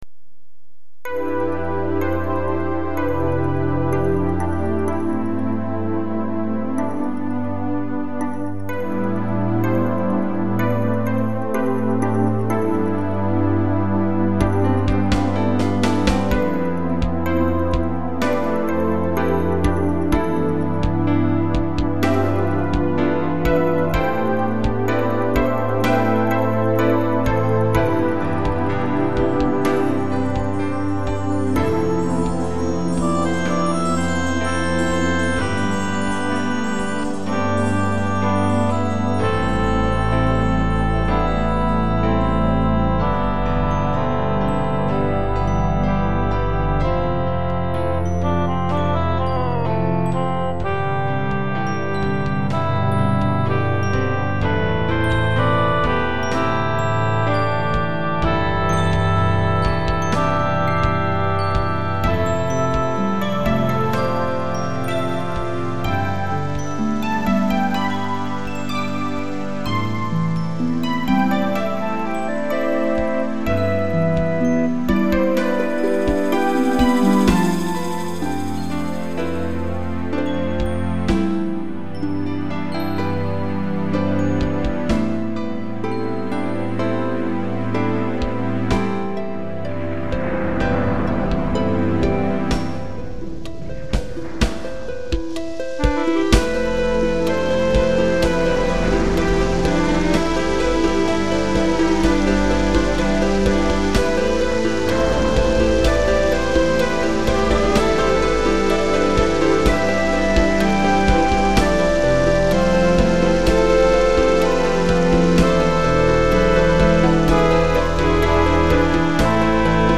解説 SEではなく、手動入力の雫が特徴。